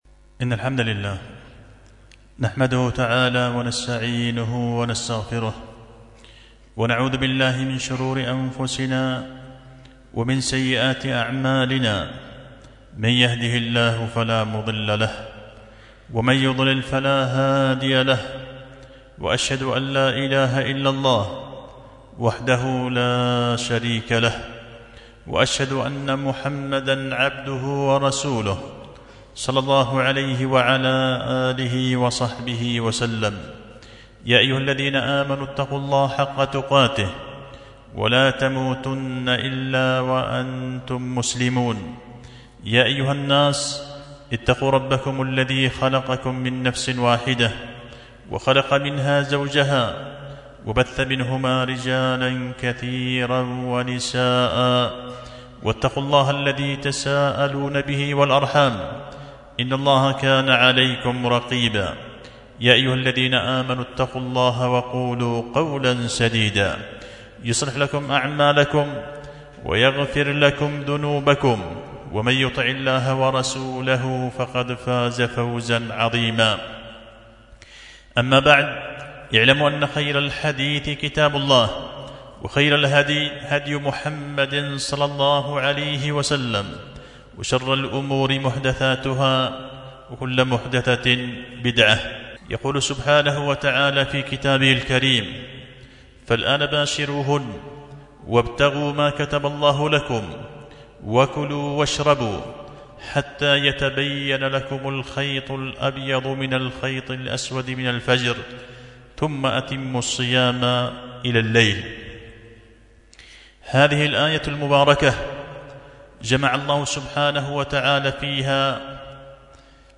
خطبة جمعة بعنوان :(الأضواء اللامعة في تفسير آية الصيام الجامعة)